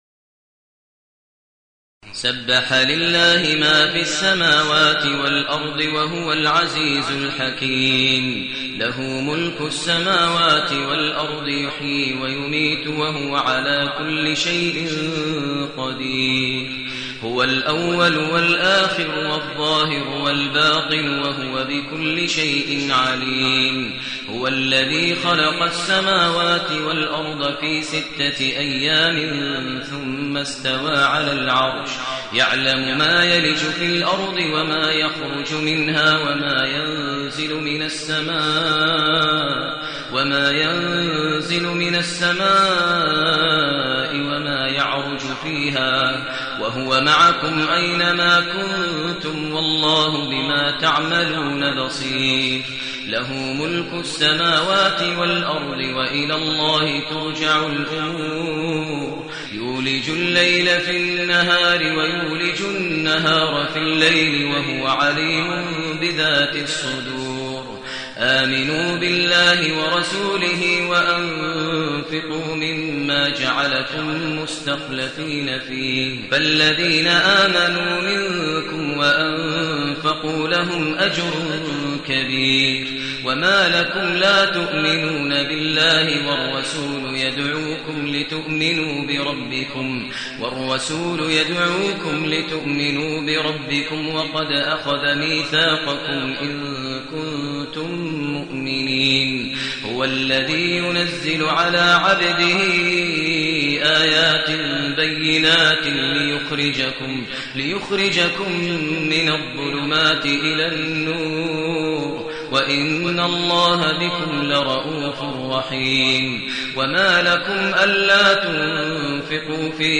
المكان: المسجد الحرام الشيخ: فضيلة الشيخ ماهر المعيقلي فضيلة الشيخ ماهر المعيقلي الحديد